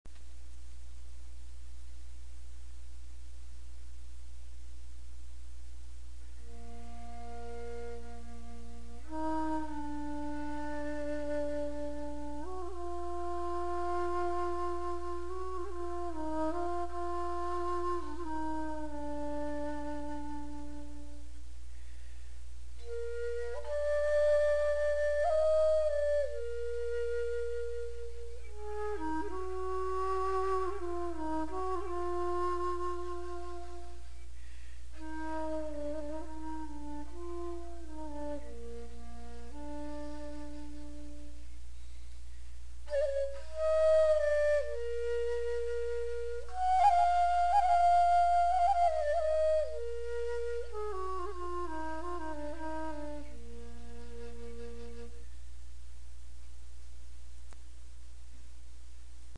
For the more technical amongst you I ordered custom made 58″ Bb Pentatonic Tranverse Bamboo Flute Staff.
It’s so soothing, calming, it cuts right through you !
custom_flute.wav